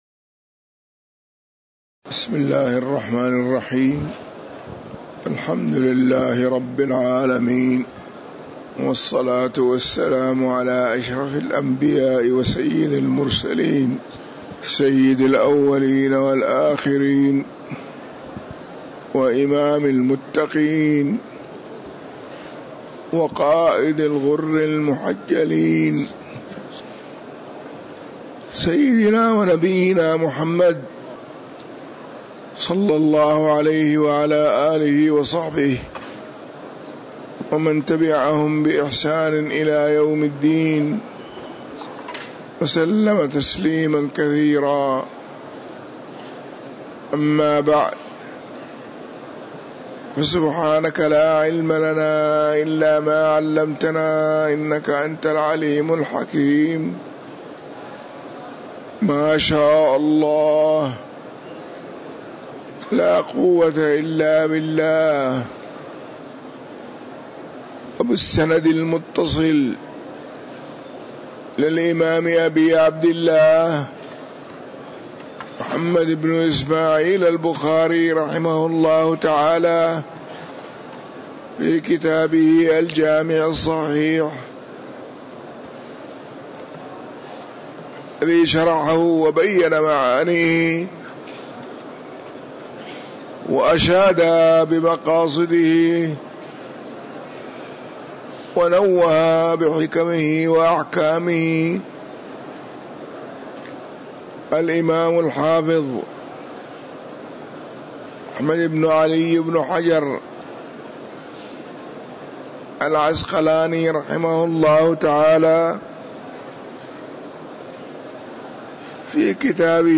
تاريخ النشر ٢٢ جمادى الأولى ١٤٤٥ هـ المكان: المسجد النبوي الشيخ